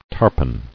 [tar·pon]